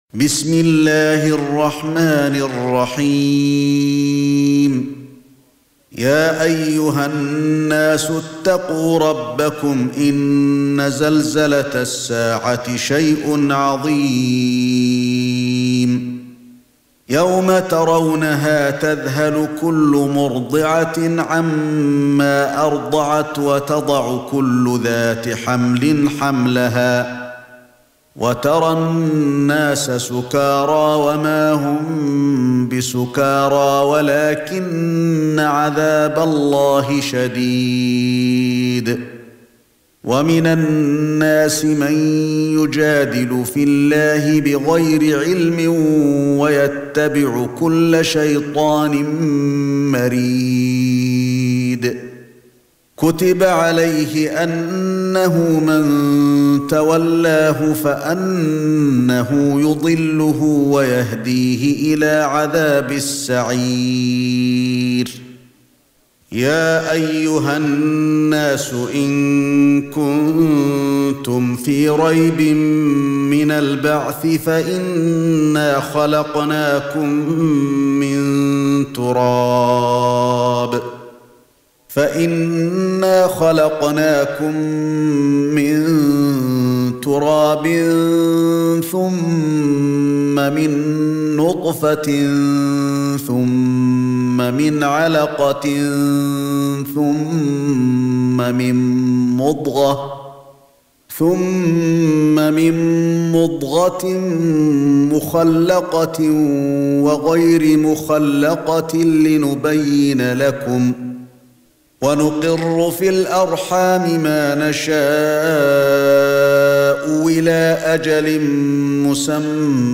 سورة الحج ( برواية قالون ) > مصحف الشيخ علي الحذيفي ( رواية قالون ) > المصحف - تلاوات الحرمين